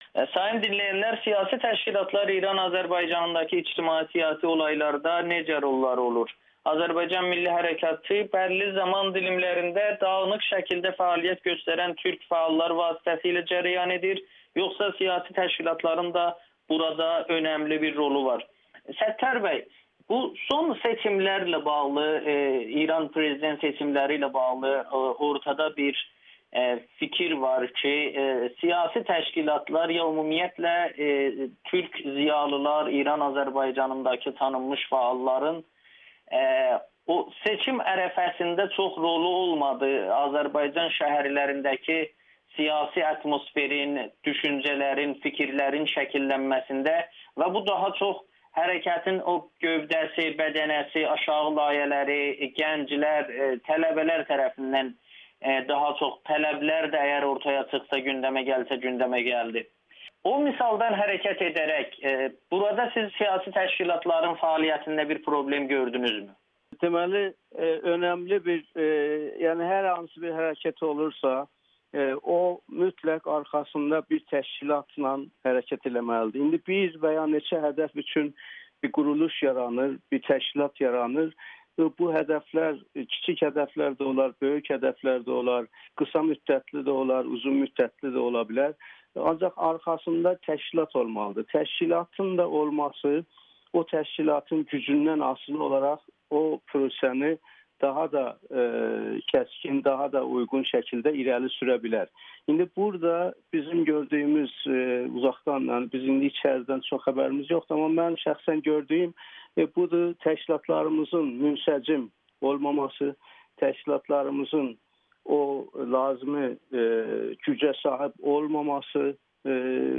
Təşkilatın olmaması hərəkatın şüarlarını boşa çıxarır [Audio-Müsahibə]